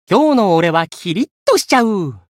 觉醒语音 今天的我可是充满干劲哦～ 今日の俺はキリッとしちゃう～ 媒体文件:missionchara_voice_635.mp3